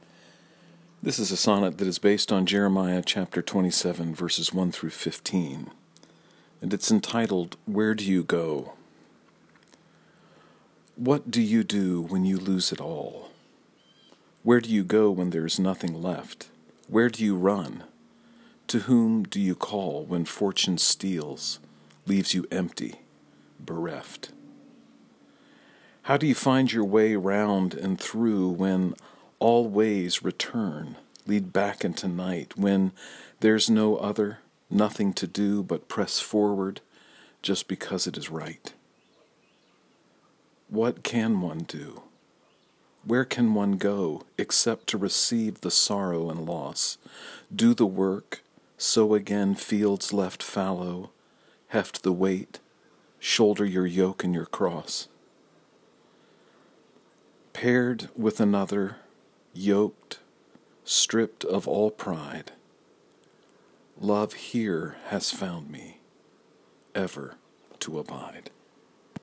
If it’s helpful, you may listen to me read the sonnet via the player below.